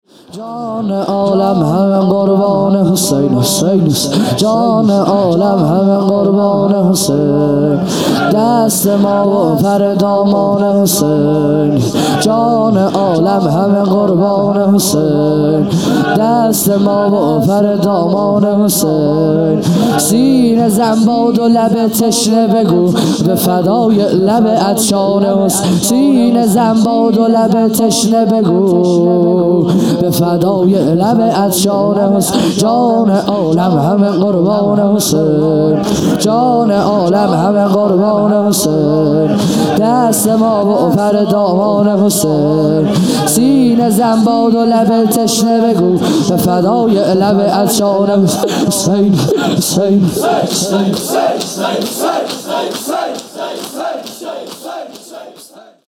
خیمه گاه - هیئت بچه های فاطمه (س) - شور | جان عالم همه قربان حسین
محرم 1441 | صبح نهم